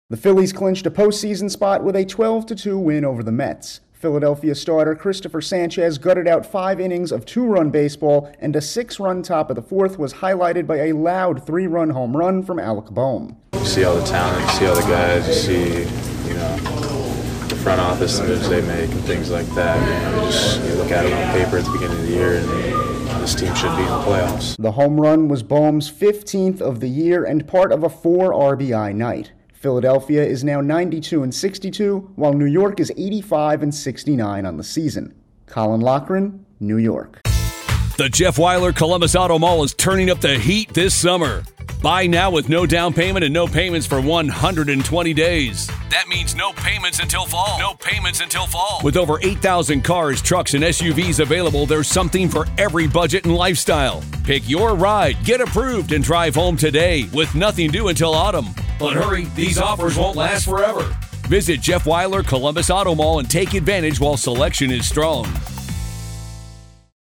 The Phillies celebrate after defeating the Mets. Correspondent